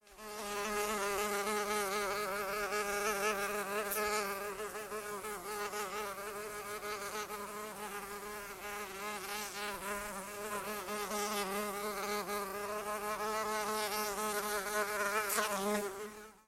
Звуки мухи
Жужжание мухи в полёте